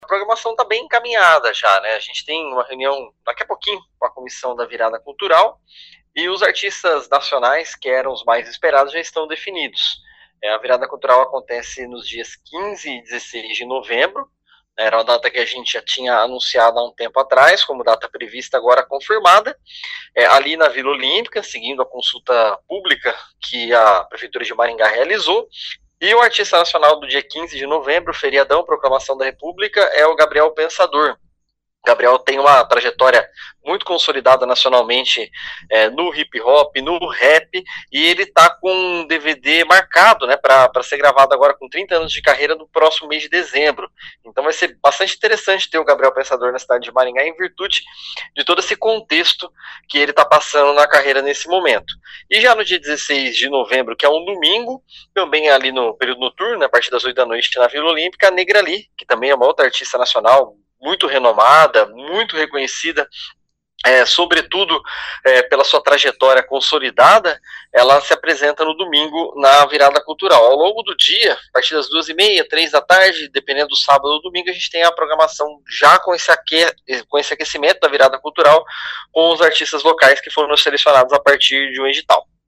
Ouça o que diz o secretário de Cultura Tiago Valenciano: